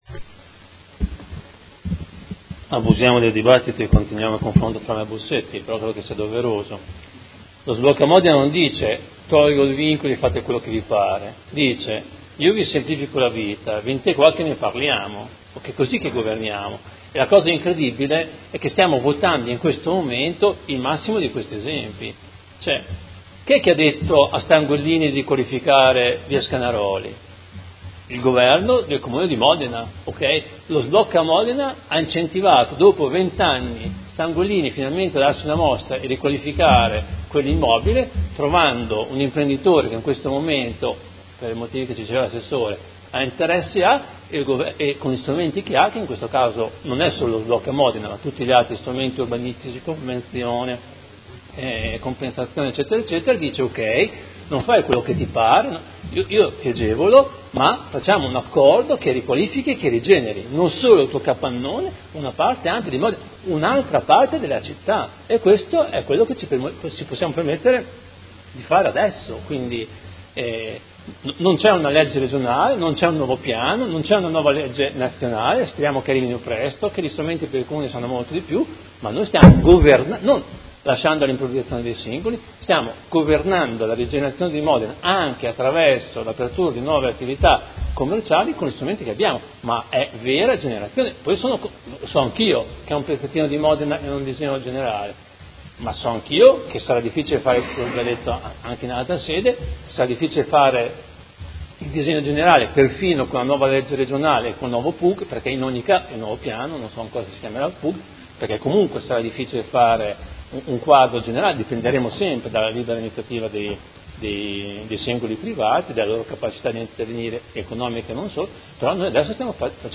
Fabio Poggi — Sito Audio Consiglio Comunale
Seduta del 13/07/2017 Dichiarazione di voto.